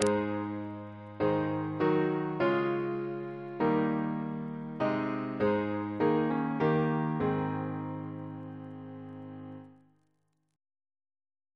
Single chant in A♭ Composer: Thomas Tomkins (1573-1656) Reference psalters: ACB: 155